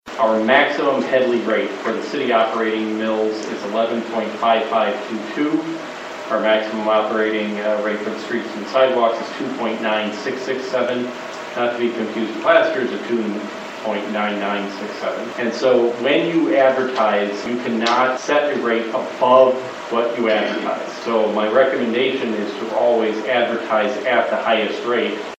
Wednesday night, City Clerk Ken Rhodes presented a proposed tax rate to the Commissioners.